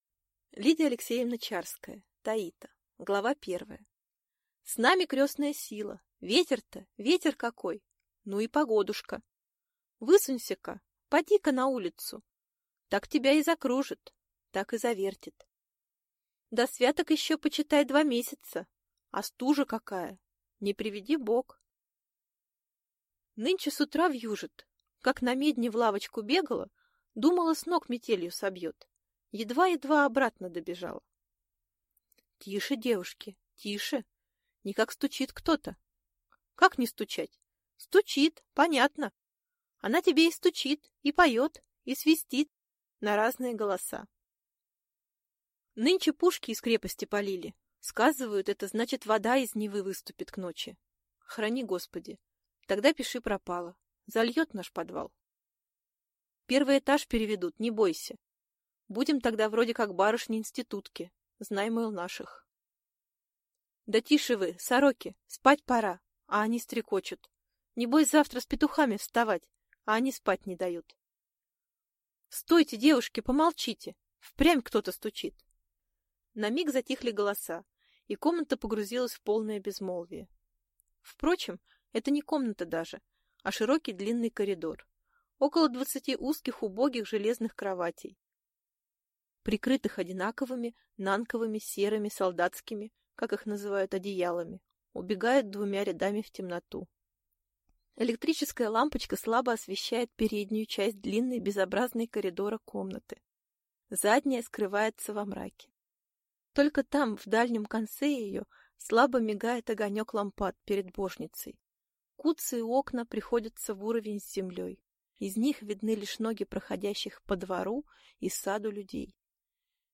Аудиокнига Таита | Библиотека аудиокниг